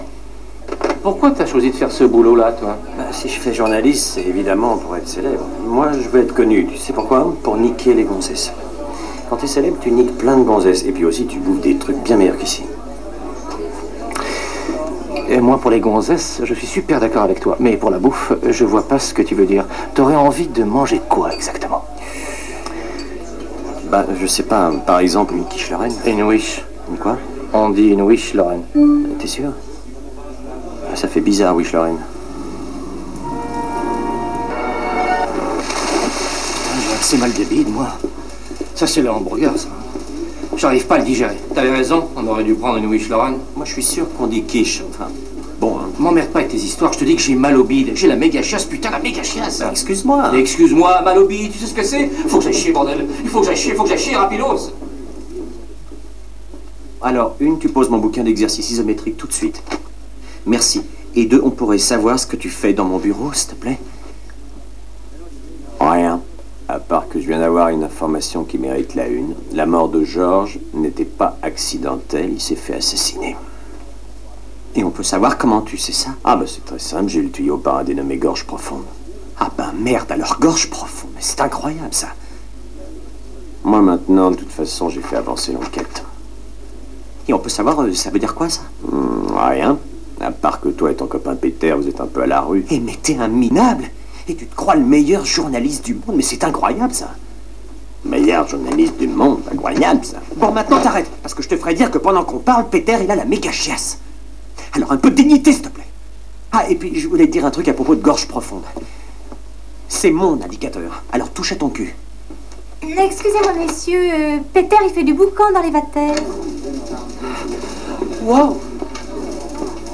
je me suis fait des petites compiles avec des extraits du films si vous voulez.....